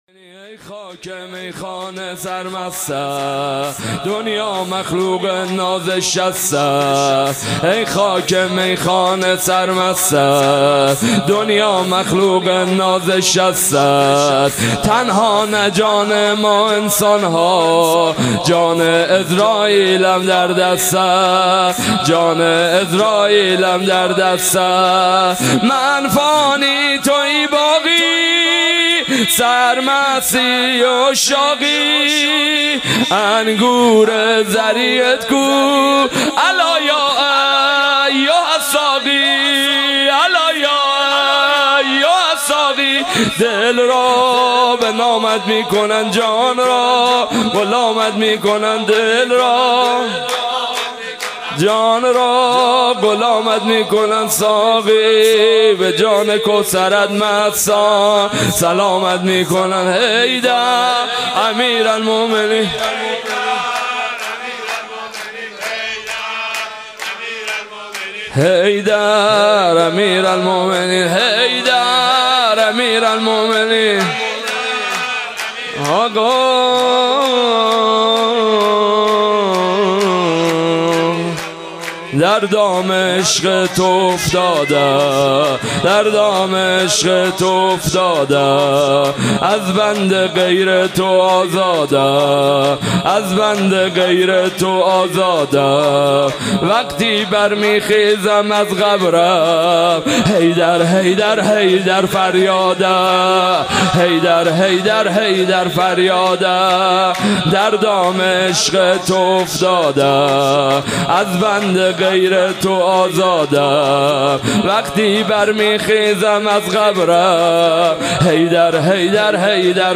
سرود - ای خاک میخانه سر مستت
ولادت امام علی (ع)